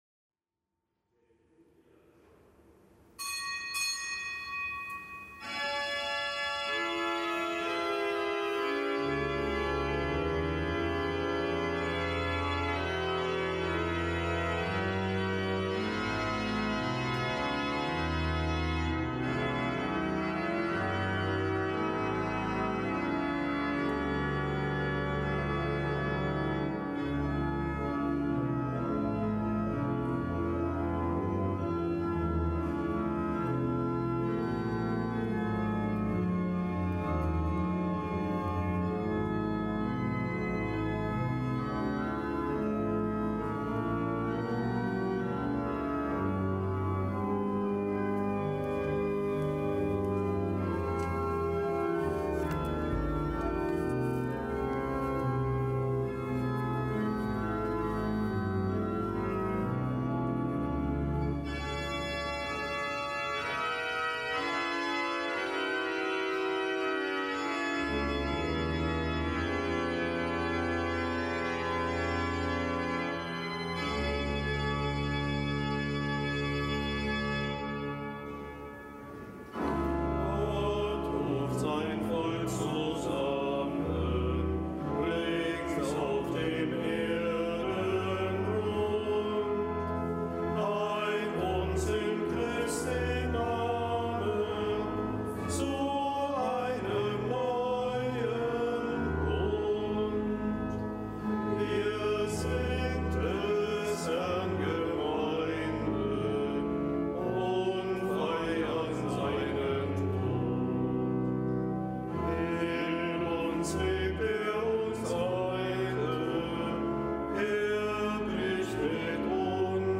Kapitelsmesse am Mittwoch der ersten Woche im Jahreskreis
Kapitelsmesse aus dem Kölner Dom am Mittwoch der ersten Woche im Jahreskreis.